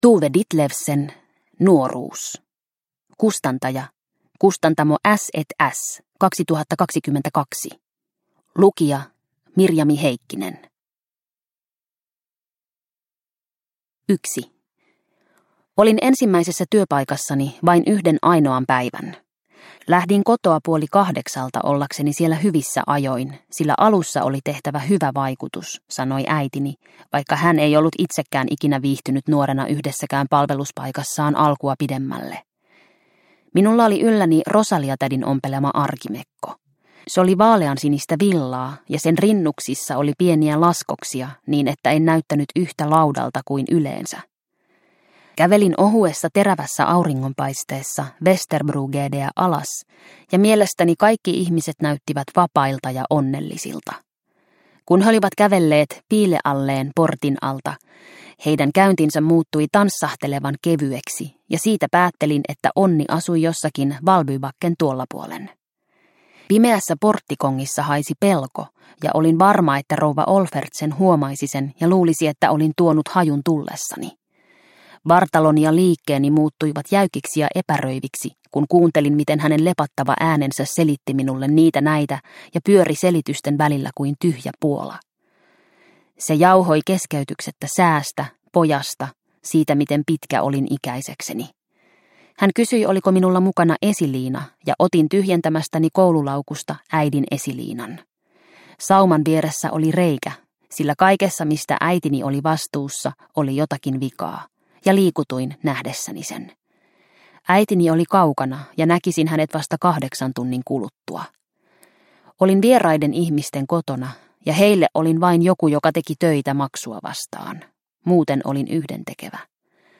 Nuoruus – Ljudbok – Laddas ner